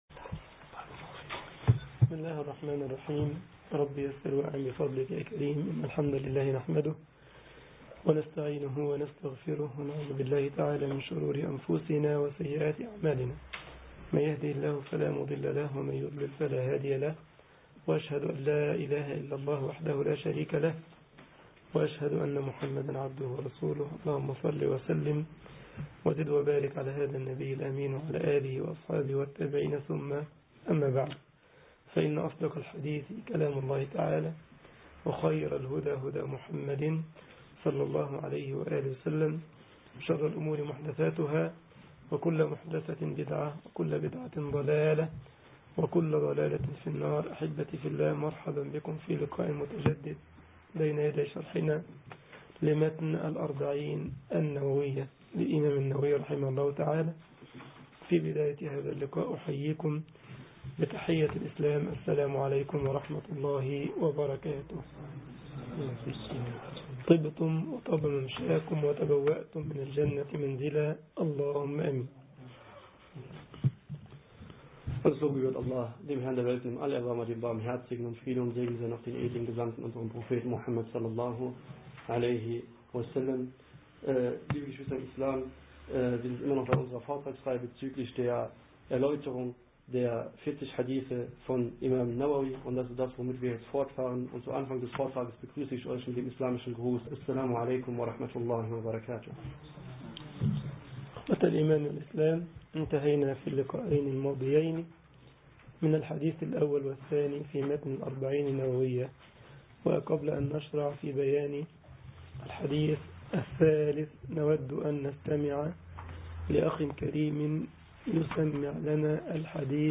محاضرة
جمعية الشباب المسلمين بسلزبخ ـ ألمانيا